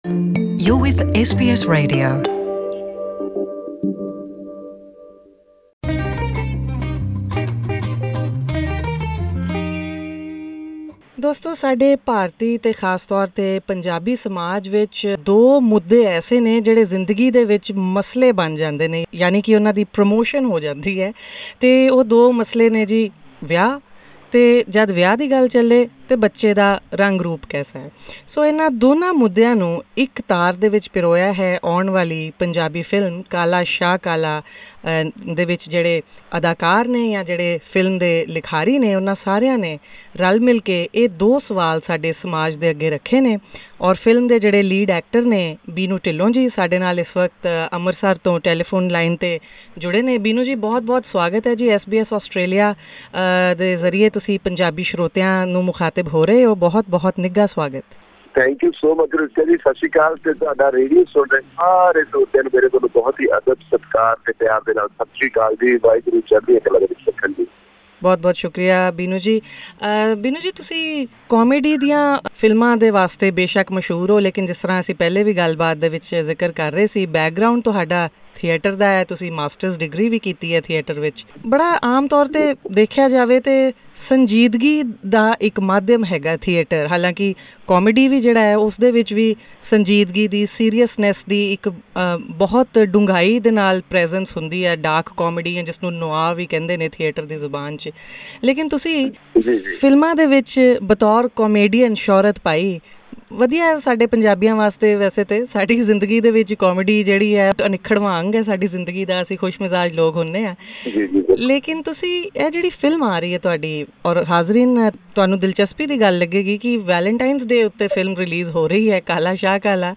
Source: Facebook/Binnu Dhillon In an interview with SBS Punjabi , Mr Dhillon says he isn’t serving Punjabi.